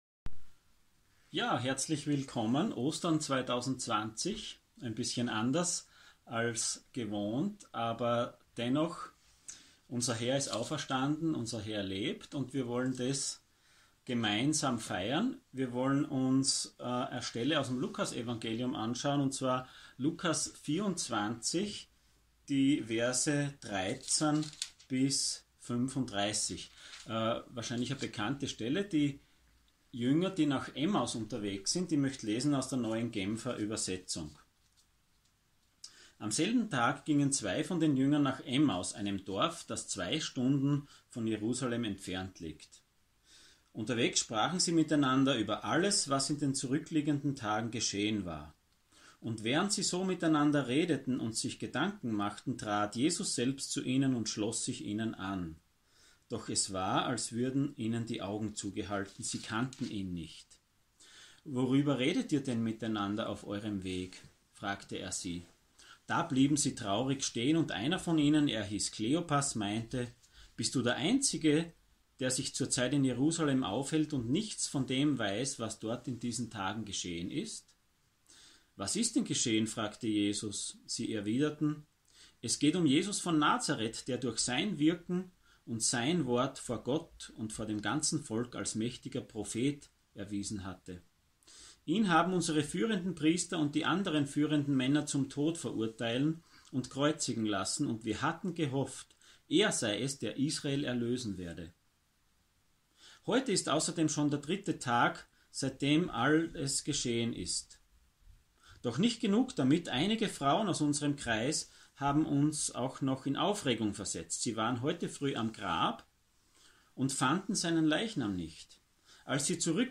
Passage: Luke 24:13-35 Dienstart: Sonntag Morgen